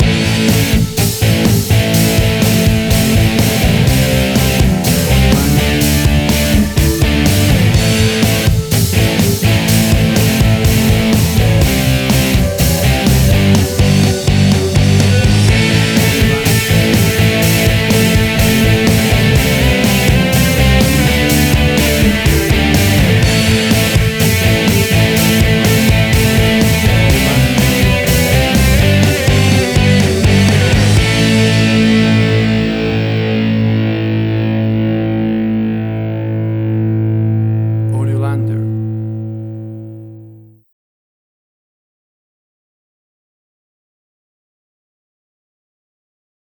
A classic big rock riff guitar led track!
WAV Sample Rate: 16-Bit stereo, 44.1 kHz
Tempo (BPM): 124